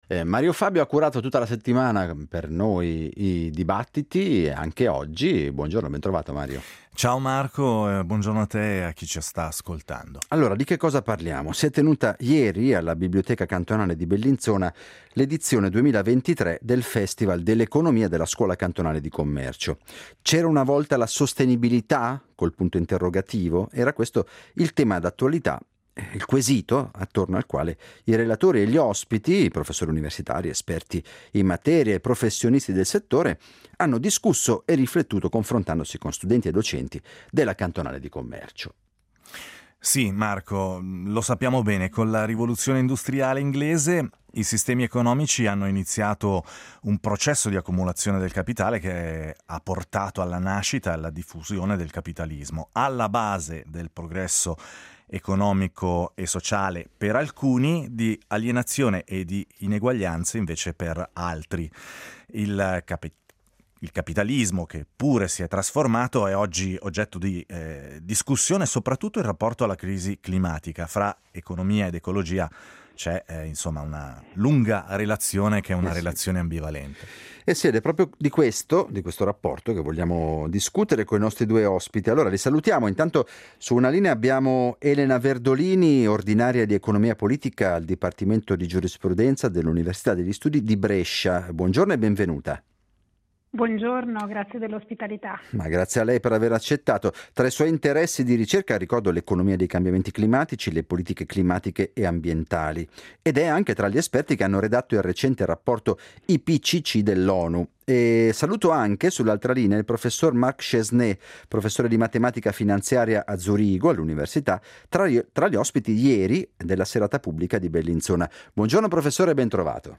Si è tenuta alla biblioteca cantonale di Bellinzona la conferenza di chiusura dell’edizione 2023 del Festival dell’economia della Scuola Cantonale di Commercio.